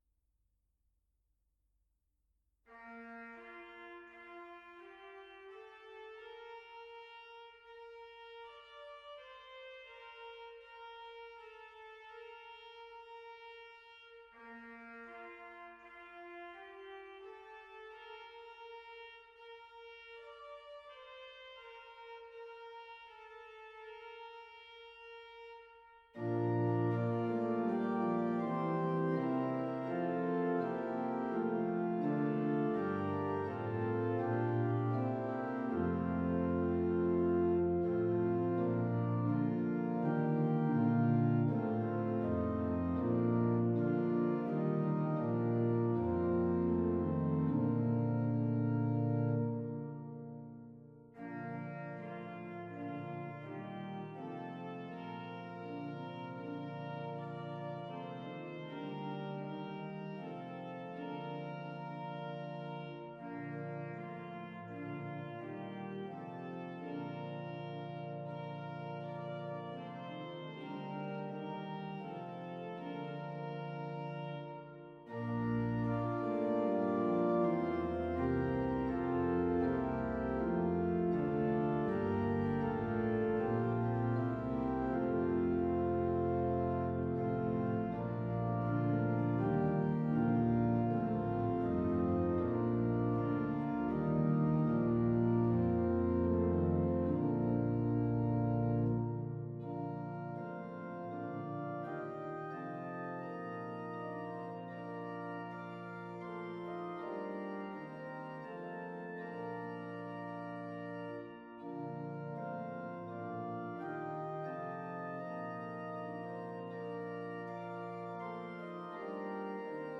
In the absence of our usual Palm Sunday Service the following scriptures, traditional Palm Sunday hymns of celebration and their words are being provided for your worship.